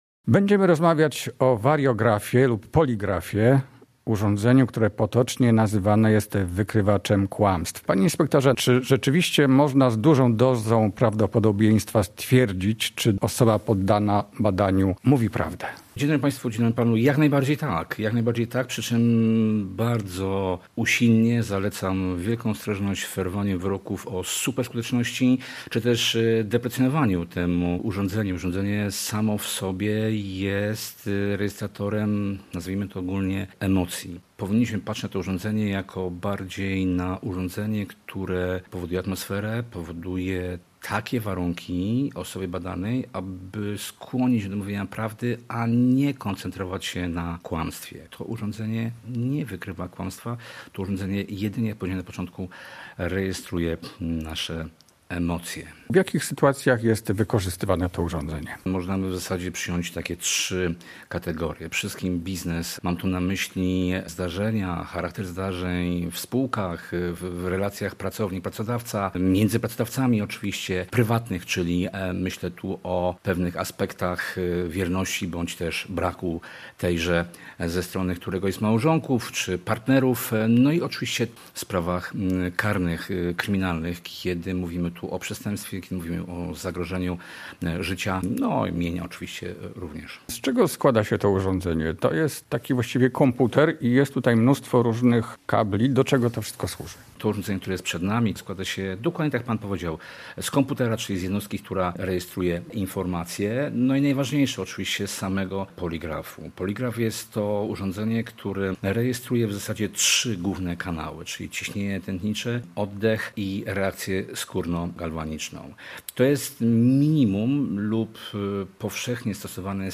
Do czego służy w postępowaniach karnych i czy można wpływać na wyniki przeprowadzanych nim badań? O tym, w rozmowie z gościem audycji.